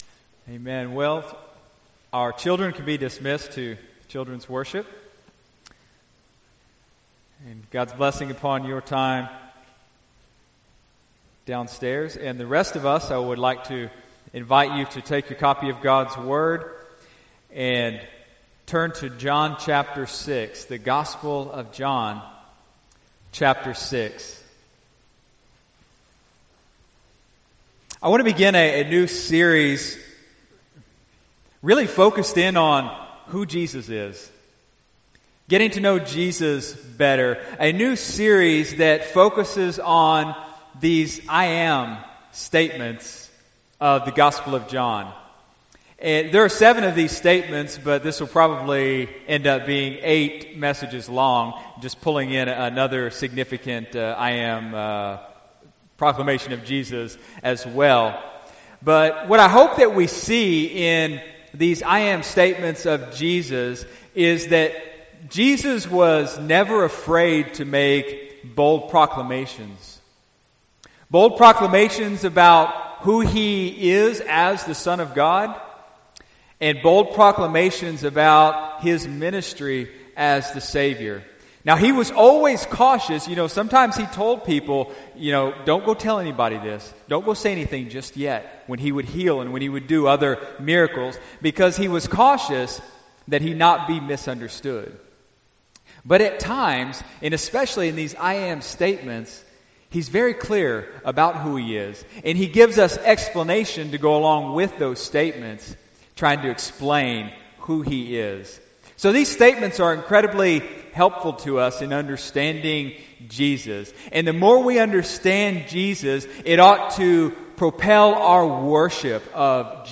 September 29, 2019 (Sunday Morning Service)